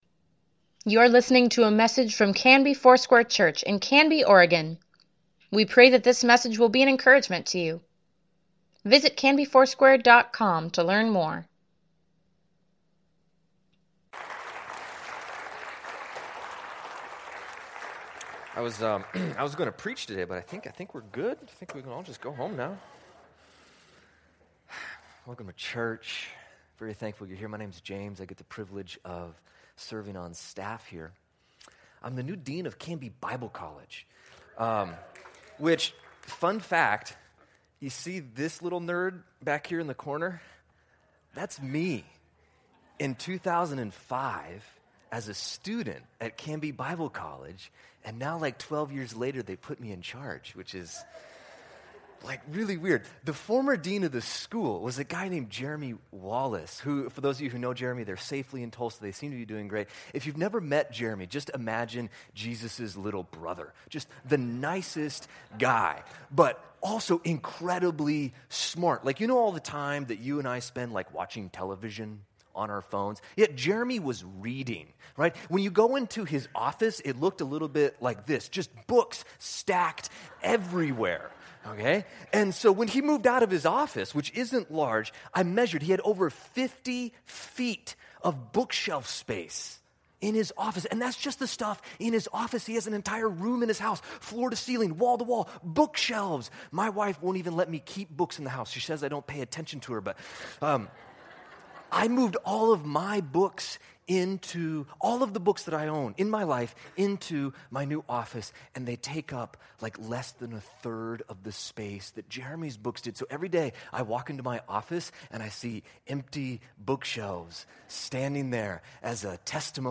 Weekly Email Water Baptism Prayer Events Sermons Give Care for Carus This is Us: The Family Mission September 24, 2017 We exist to make disciples who make disciples for Jesus.